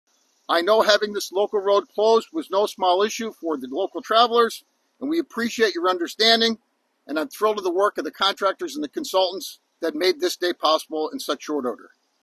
Ceremonies were held earlier today to celebrate the reopening of the Heshbon Bridge.
The bridge that carries Route 259 over Blacklick Creek was completely closed to traffic during that time, and PennDOT Secretary Mike Carroll acknowledged that the traffic change was hard on local residents.